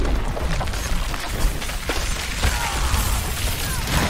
Download Deep Freeze sound effect for free.